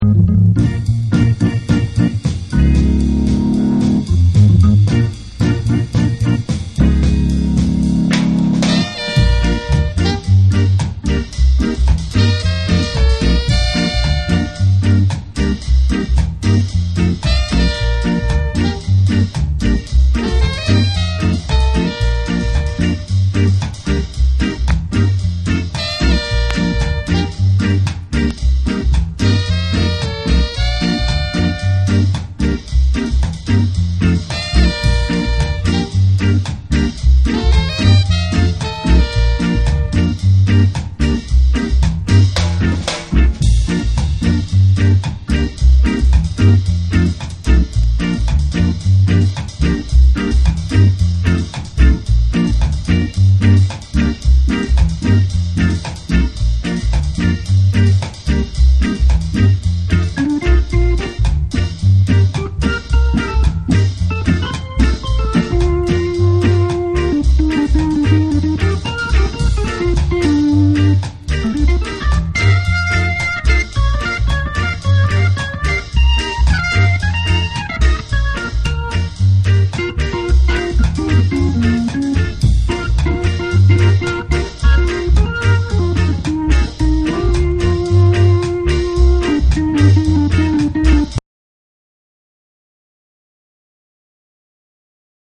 REGGAE & DUB